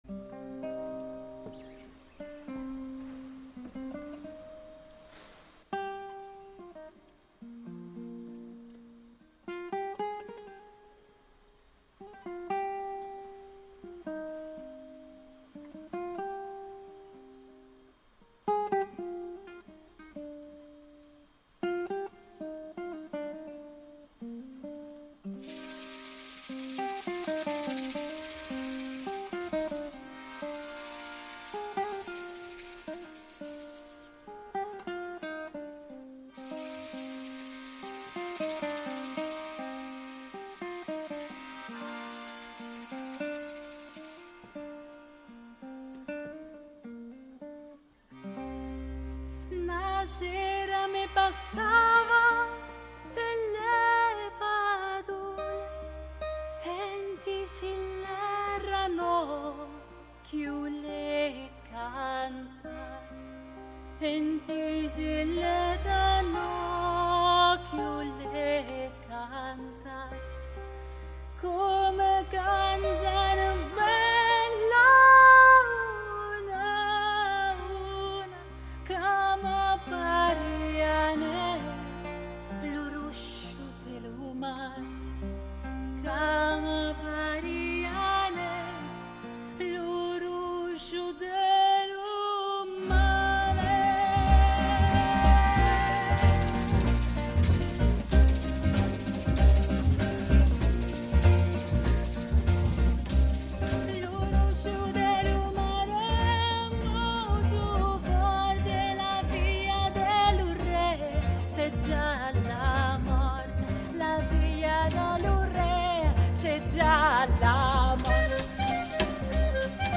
Una figura meravigliosamente atipica nel panorama musicale, capace di spaziare dal barocco al repertorio popolare di vari paesi.
Possedendo una “voce” che sfugge ad ogni tipo di classificazione per la particolarità del timbro, per l’estensione e per la spiccata versatilità nello spaziare tra generi musicali diversi,